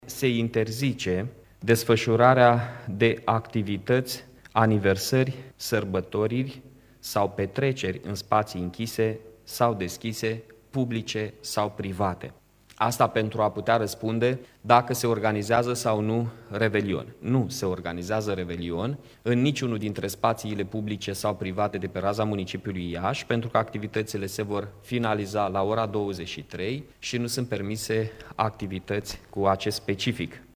Primarul Mihai Chirica: